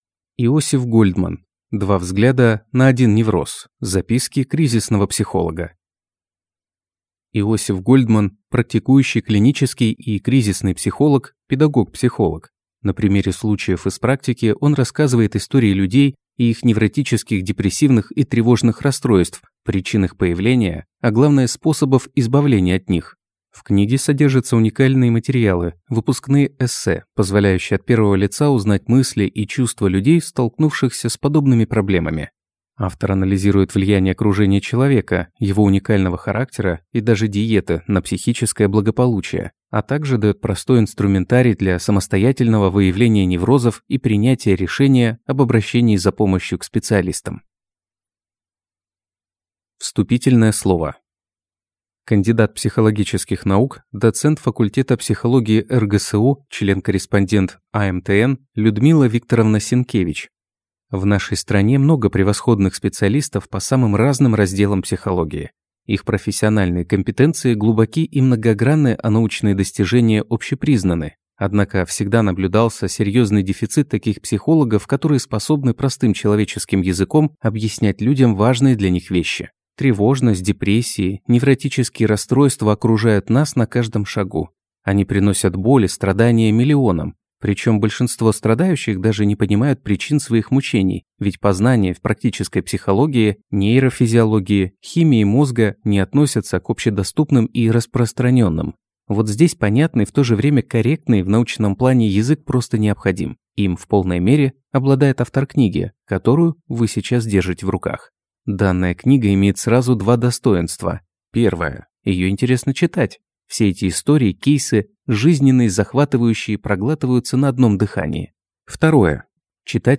Аудиокнига Два взгляда на один невроз. Записки кризисного психолога | Библиотека аудиокниг